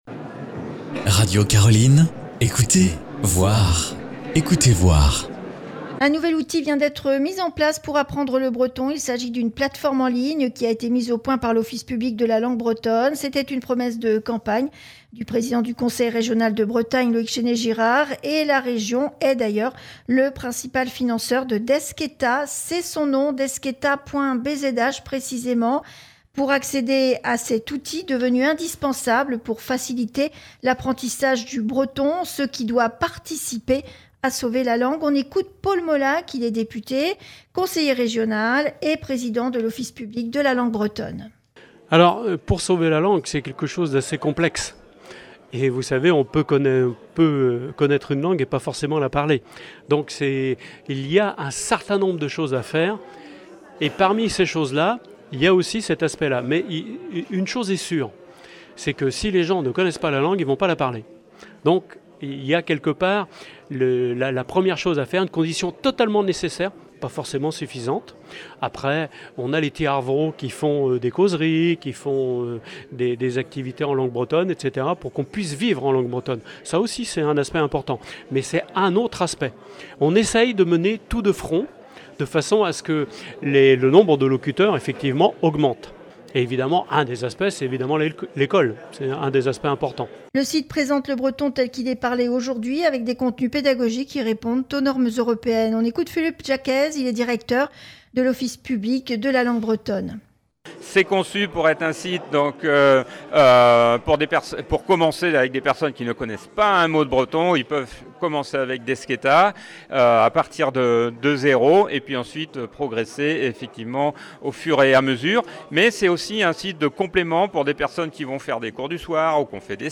Retrouvez  la chronique Ecouter Voir, le rendez-vous culturel de Radio Caroline, avec les interventions de Paul Molac, Conseiller régional et